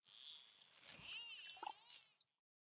Minecraft Version Minecraft Version latest Latest Release | Latest Snapshot latest / assets / minecraft / sounds / mob / dolphin / idle_water4.ogg Compare With Compare With Latest Release | Latest Snapshot
idle_water4.ogg